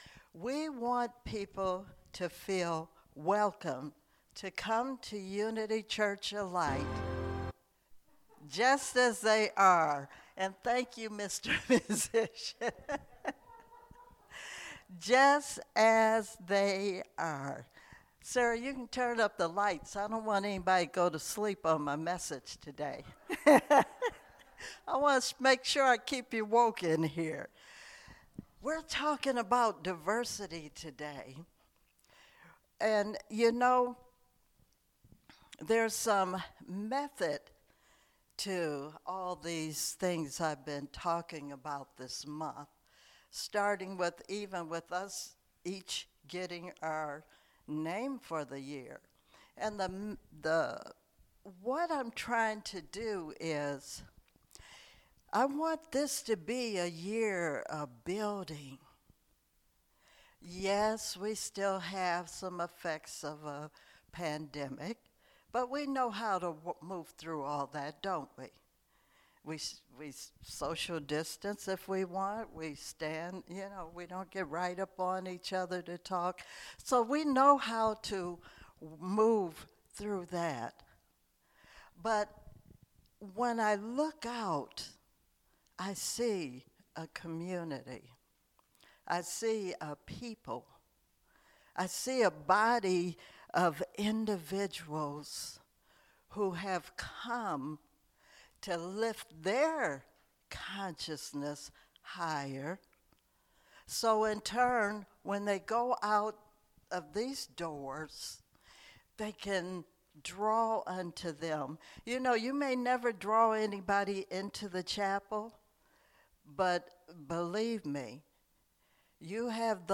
Spiritual Leader Series: Sermons 2022 Date